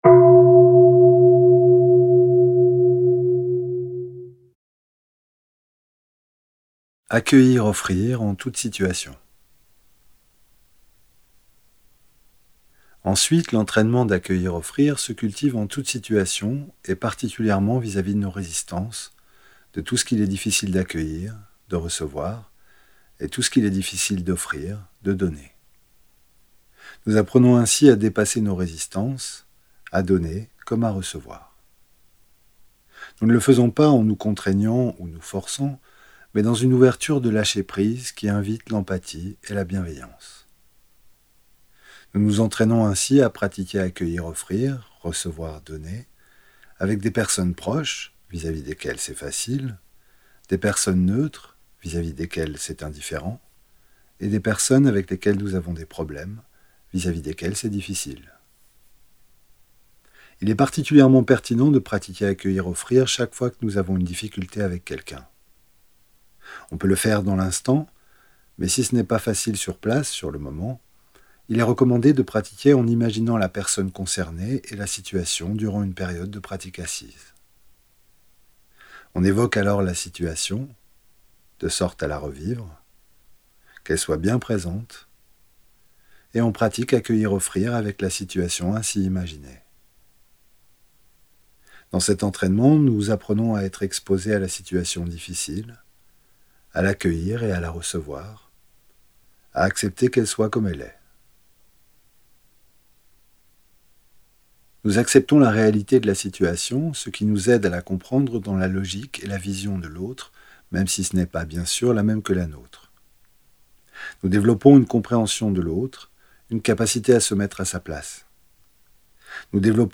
Audio homme
14.ETAPE-6-AUDIO-P39-HOMME.mp3